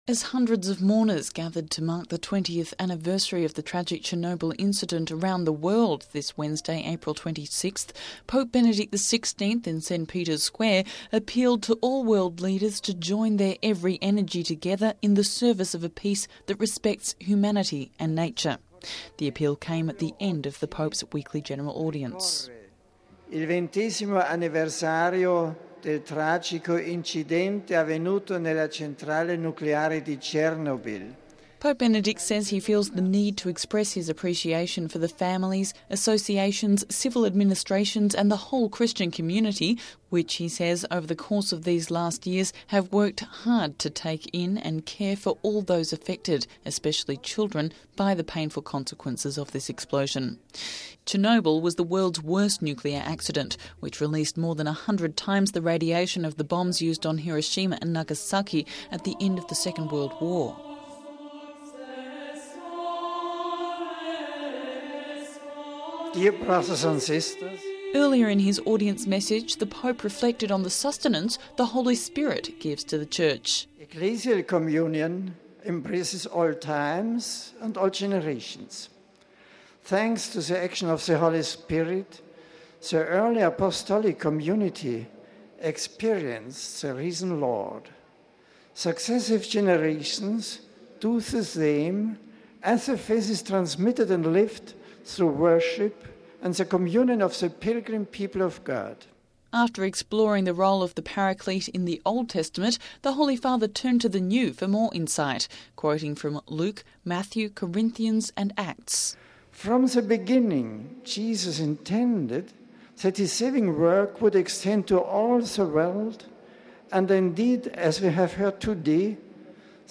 Weekly General Audience